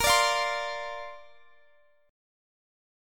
Bbadd9 Chord
Listen to Bbadd9 strummed